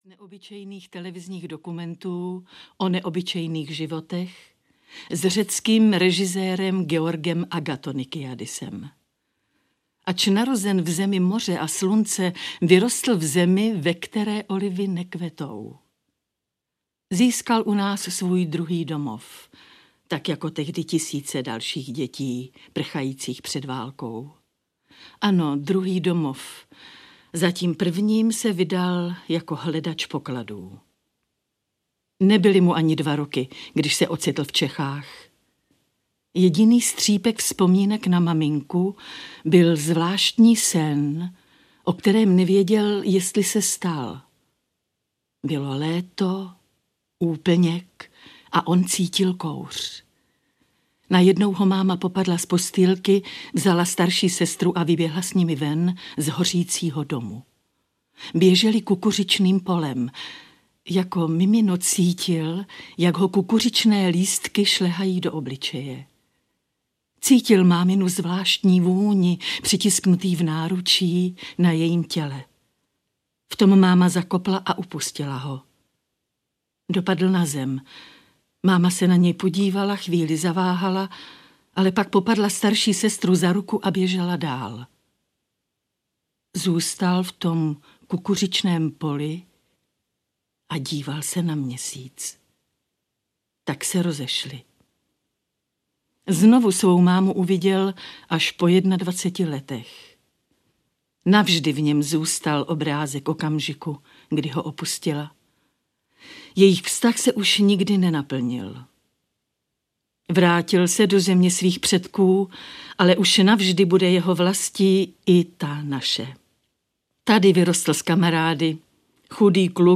Audio kniha
Vybrané kapitoly ze stejnojmenných knih načetly v Českém rozhlase Dvojka v rámci živého vysílání nedělních rán přední herecké osobnosti (Libuše Švormová, Jana Preissová, Josef Somr, Jiří Lábus, Naďa Konvalinková, Viktor Preiss, Ladislav Frej, Jan Potměšil a mnozí další).